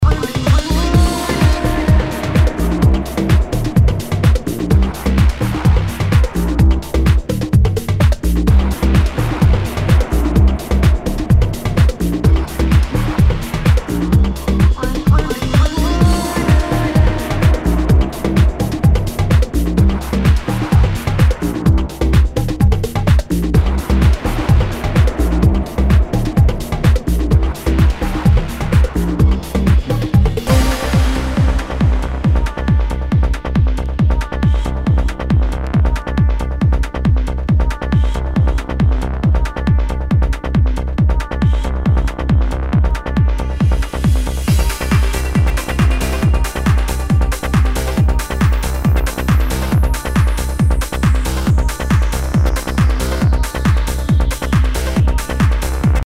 HOUSE/TECHNO/ELECTRO
プログレッシブ・ハウス！
全体にチリノイズが入ります